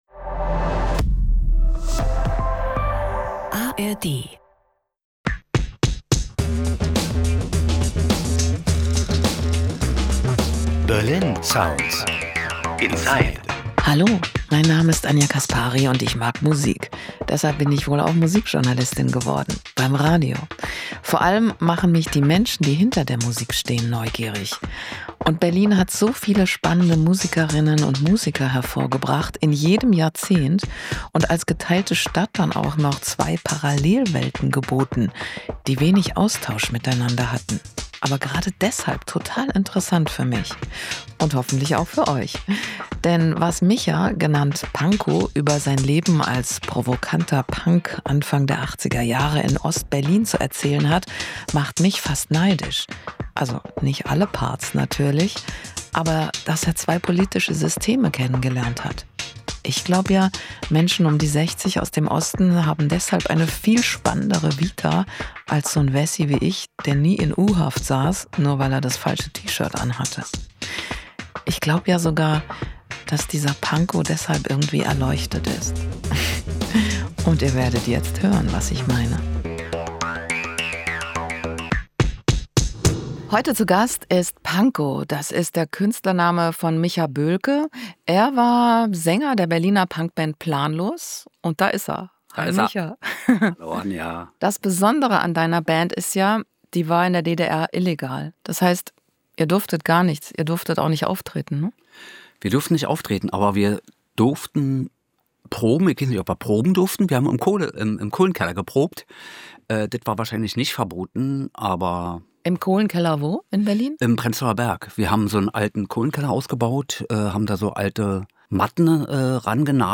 Der Musiktalk